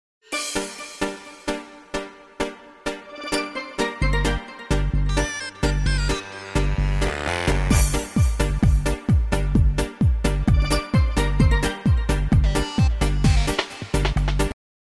• Качество: 128, Stereo
инструментальные
русский шансон